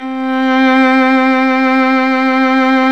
Index of /90_sSampleCDs/Roland L-CD702/VOL-1/STR_Violin 2&3vb/STR_Vln2 mf vb
STR  VL C 5.wav